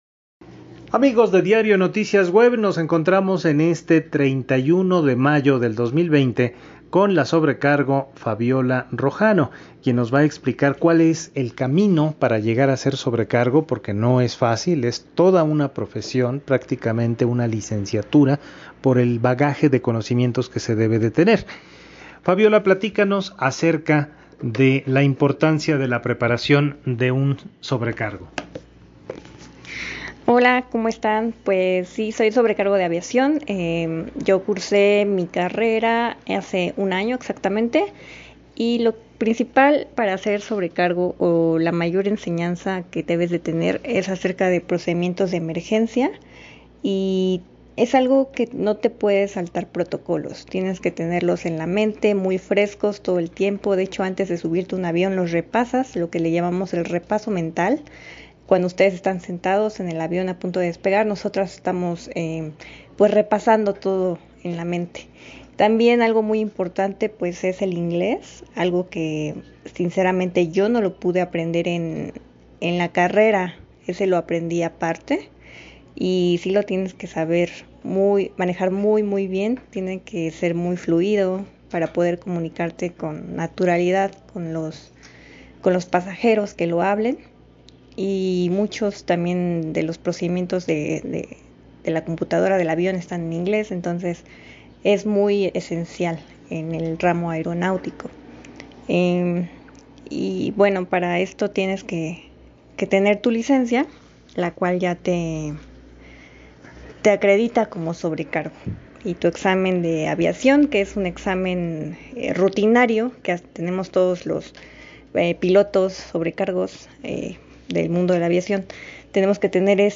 Las dos conversaciones quedaron grabados en dos audios.